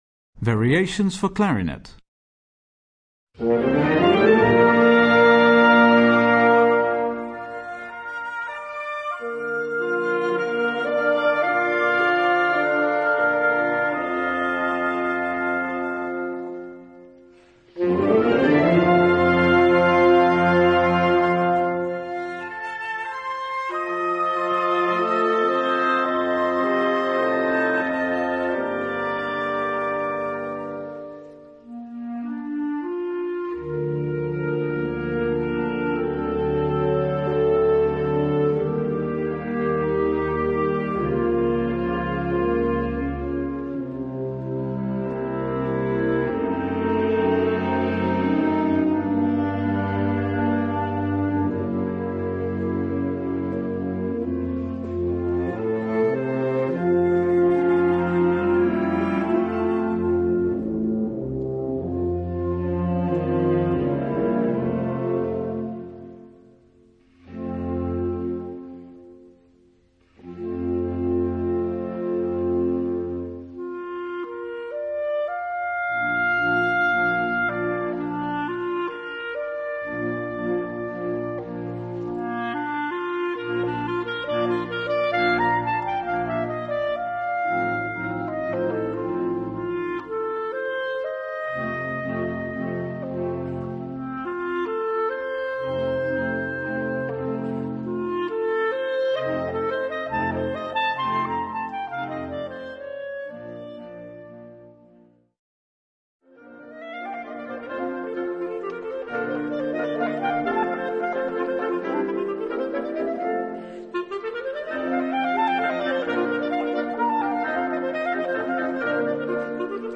B-flat Major（原曲は C Major）
高音質